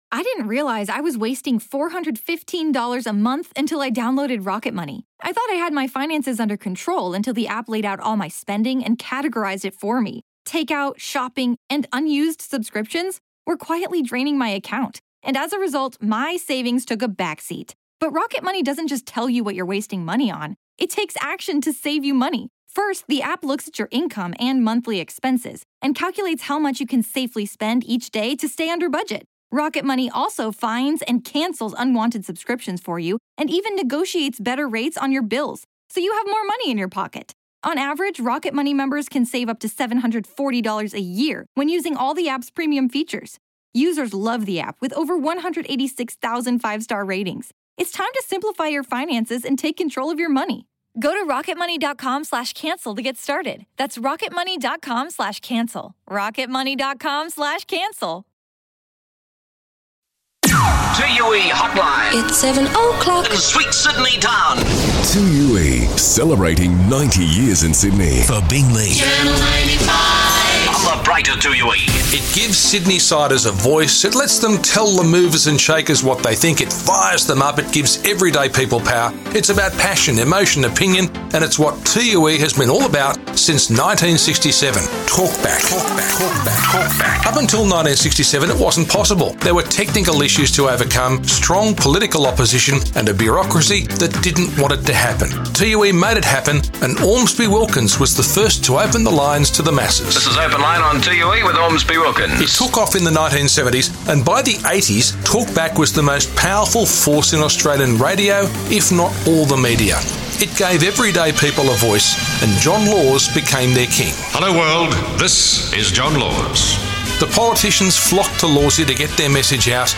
2UE 90th Birthday Flashback - Talkback